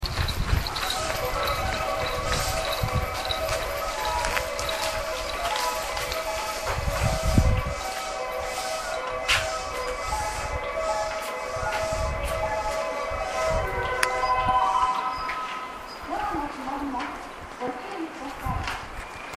２番線常磐線
発車メロディーフルコーラスです。